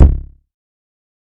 TC3Kick18.wav